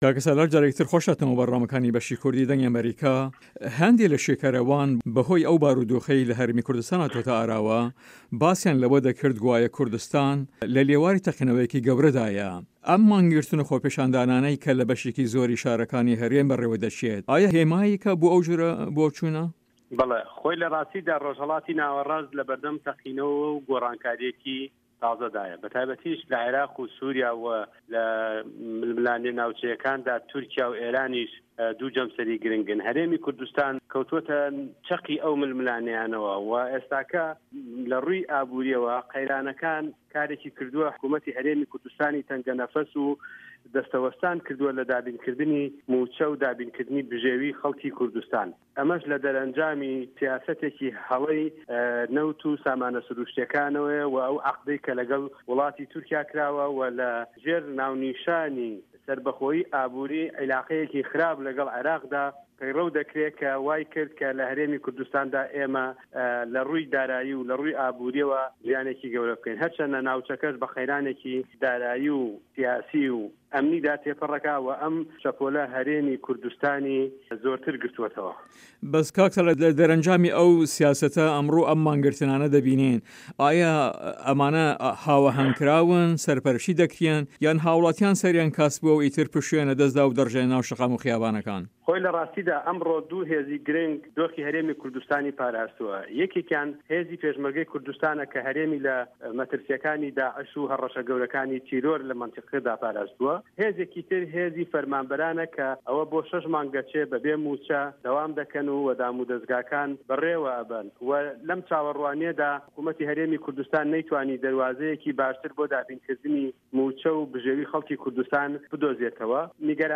سالار مەحمود ئەندام پەرلەمانی هەرێمی کوردستان لە هەڤپەیڤینێکدا لەگەڵ بەشی کوردی دەنگی ئەمەریکا دەڵێت" لە راستیدا رۆژهەڵاتی ناوەراست لە بەردەم تەقینەوە و گۆڕانکاریەکی تازەدایە، بە تاێبەتیش لە عێراق و سوریا، وە لە ململانیە ناوچەکانیشدا تورکیا و ئێرانیش دوو جەمسەری گرنگن، هەرێمی کوردستان کەوتوەتە چەقی ئەو ململانیانەوە، وە ئێستاکە لە رووی ئابوریەوە قەیرانەکان کارێکی کردووە حکومەتی هەرێمی کوردستانی تەنگە نەفەس و دەستە وەستان کردووە لە دابینکردنی موچە و داببنکردنی بژێوی خەڵکی کوردستان.